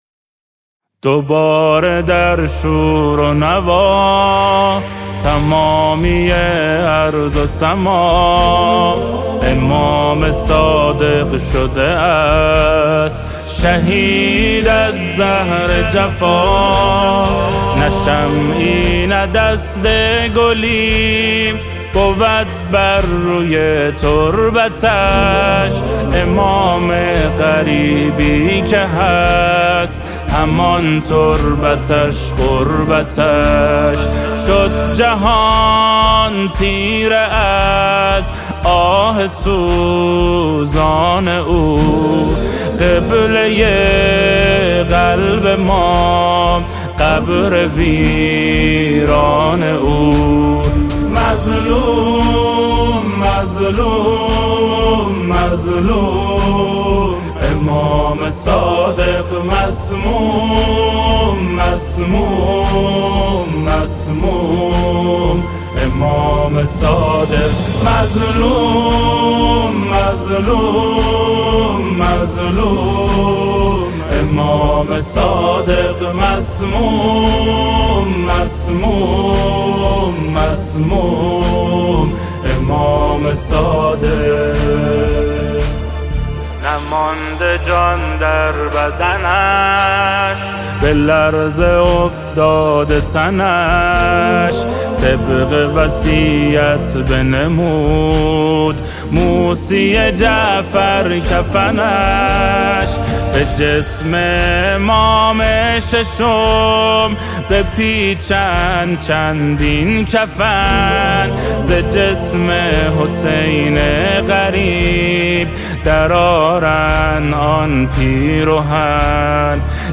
از مداحان کشور